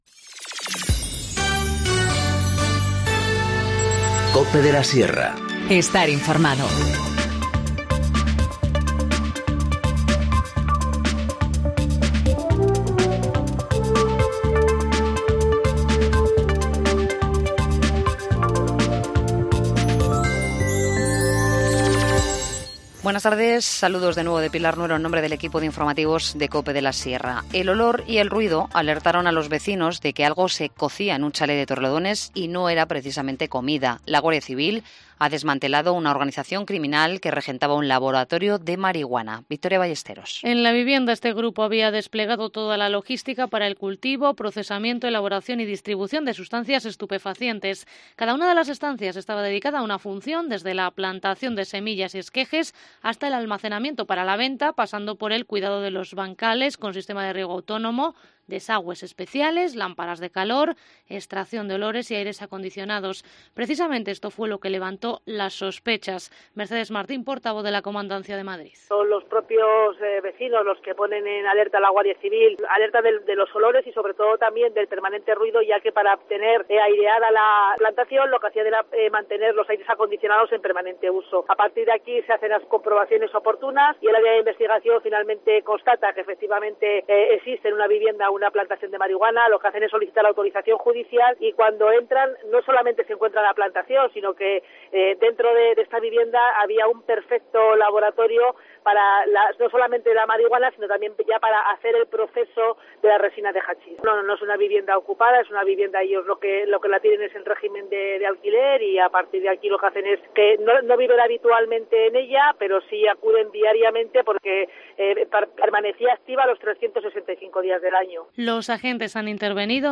Informativo Mediodía 24 mayo 14:50h